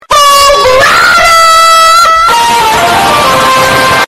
Meme Sound Effects
u3-hog-rider-bass-boosted.mp3